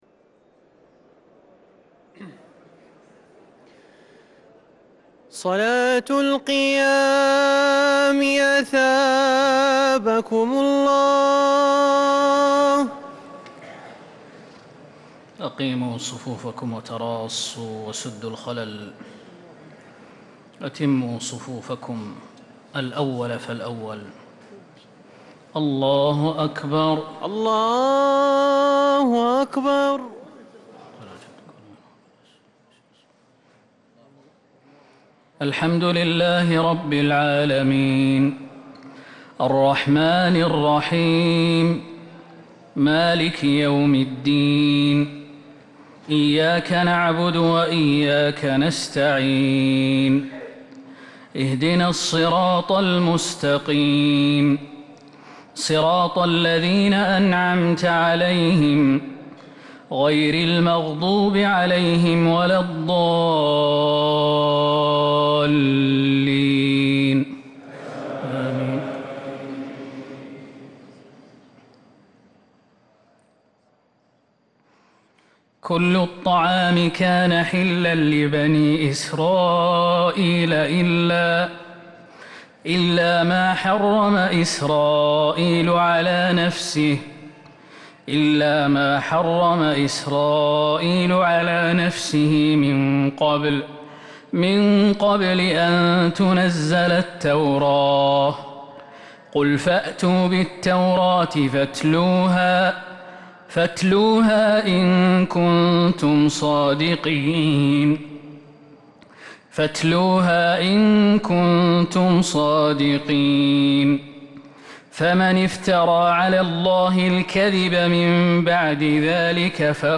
صلاة التراويح ليلة 5 رمضان 1444 للقارئ خالد المهنا - الثلاث التسليمات الأولى صلاة التراويح